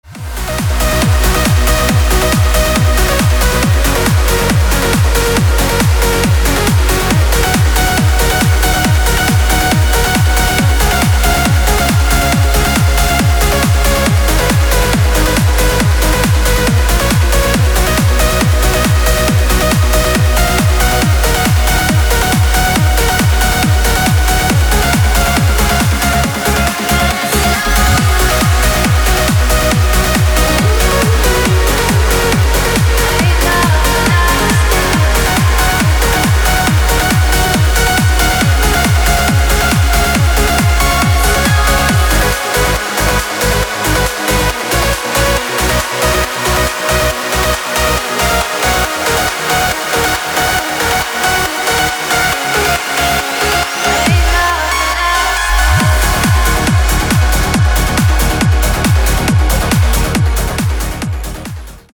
• Качество: 256, Stereo
громкие
женский голос
dance
Electronic
электронная музыка
club
красивый женский голос
Trance
vocal trance